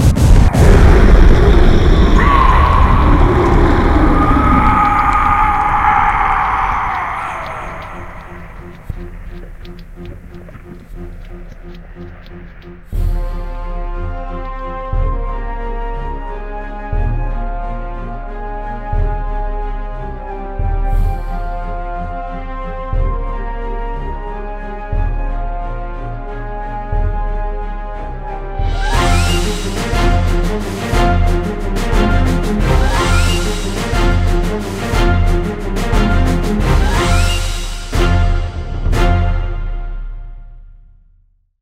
PixelPerfectionCE/assets/minecraft/sounds/mob/wither/death.ogg at mc116